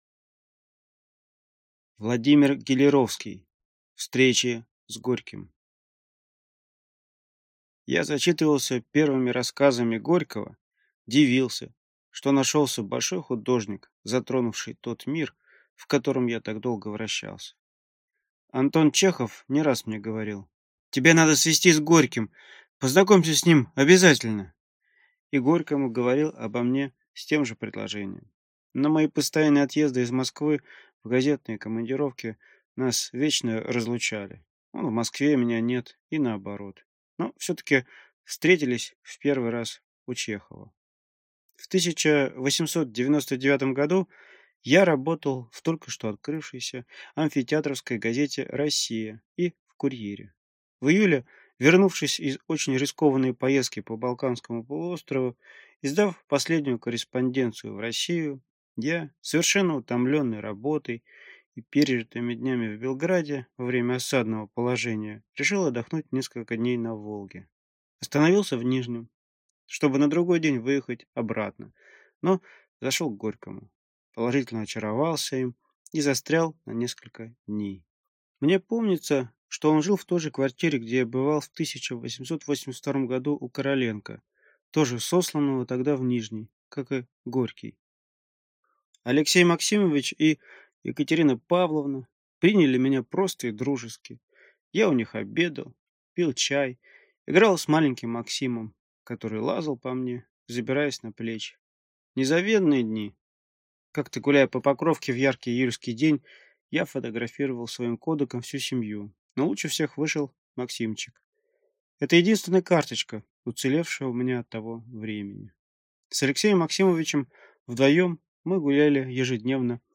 Аудиокнига Встречи с Горьким | Библиотека аудиокниг